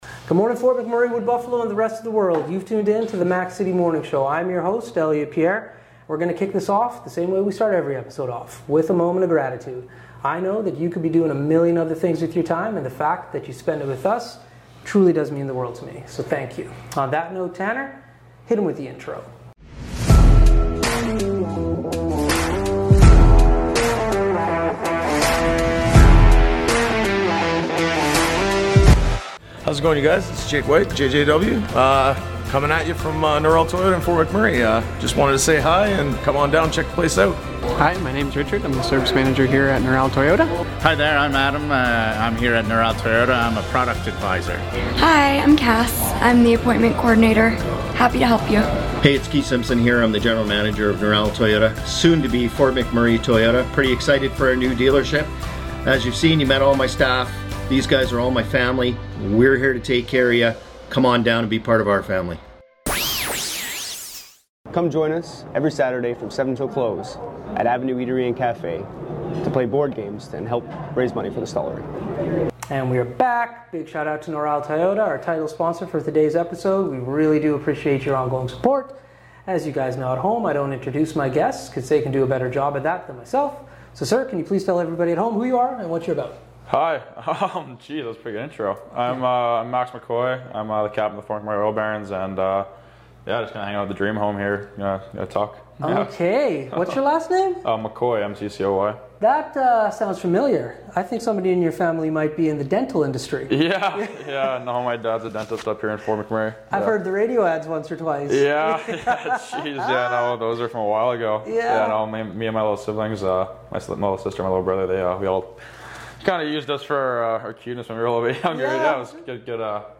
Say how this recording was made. Back on location at the Fort McMurray Oil Barons Dream Home